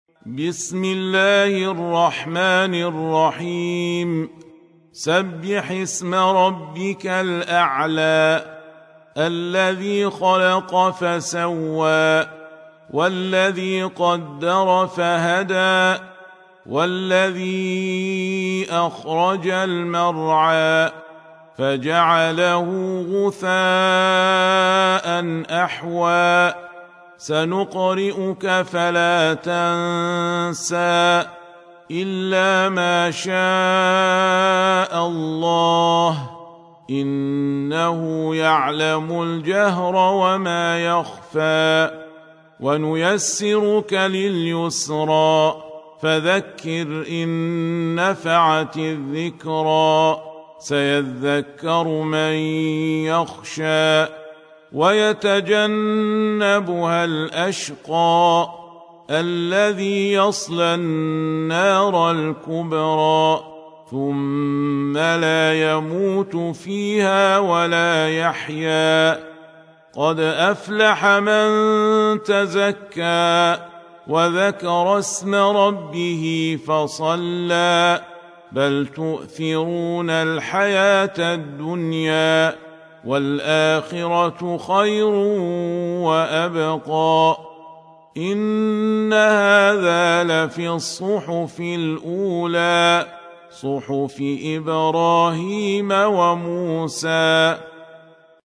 Tarteel Recitation
Surah Sequence تتابع السورة Download Surah حمّل السورة Reciting Murattalah Audio for 87. Surah Al-A'l� سورة الأعلى N.B *Surah Includes Al-Basmalah Reciters Sequents تتابع التلاوات Reciters Repeats تكرار التلاوات